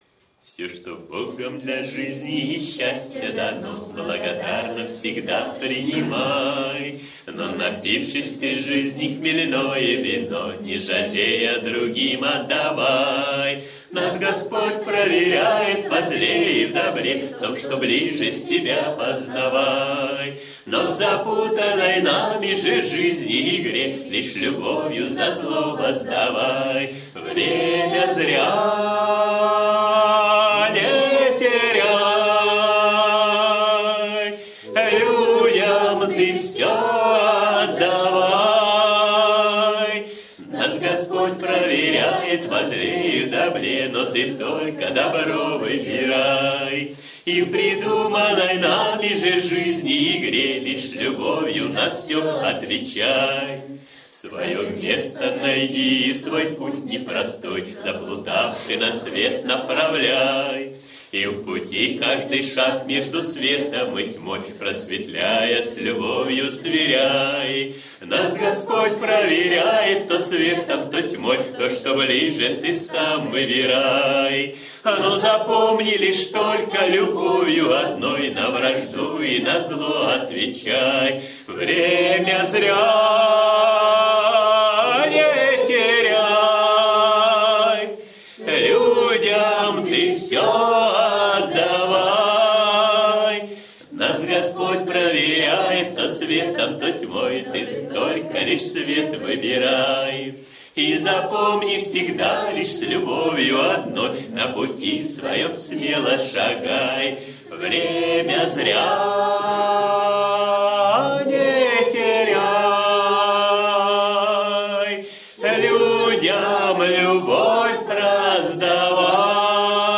кавер-версия на мотив песни